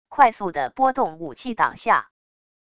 快速地拨动武器挡下wav音频